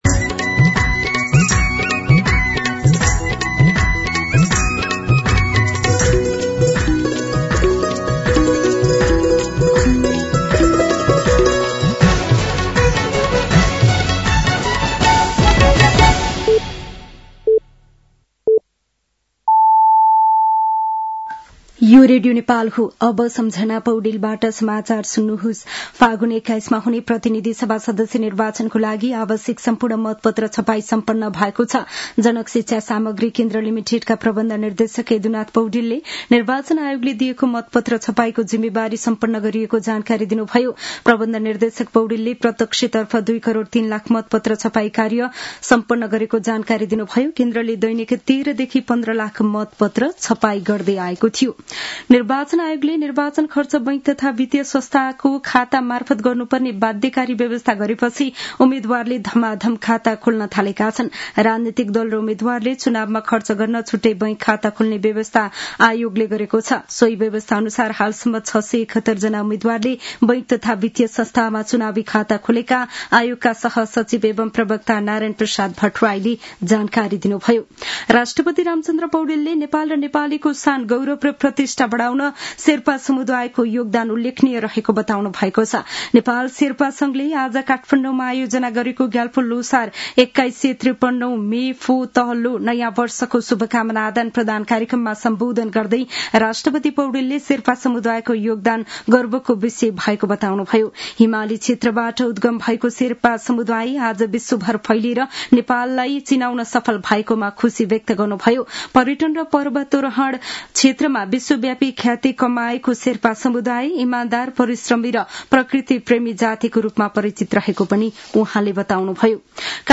साँझ ५ बजेको नेपाली समाचार : ५ फागुन , २०८२
5-pm-news-11-5.mp3